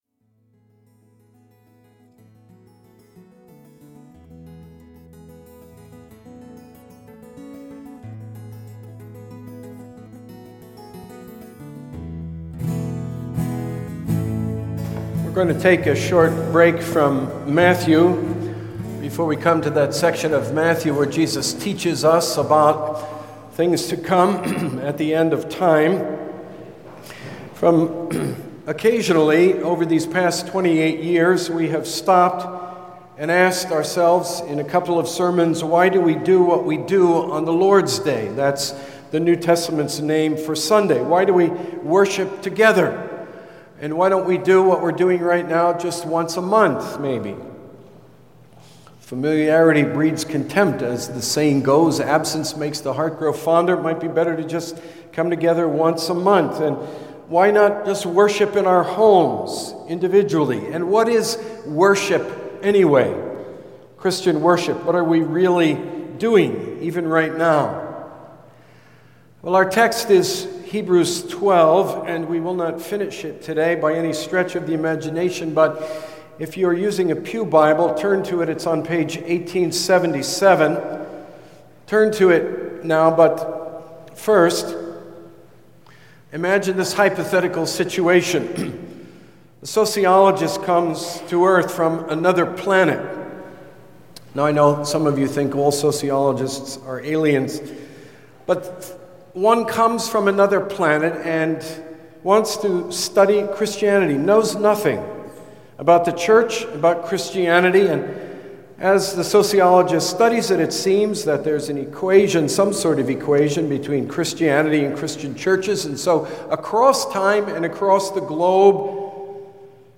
(sermon audio)
May 31, 2009 at 11:00 am · Filed under Sermons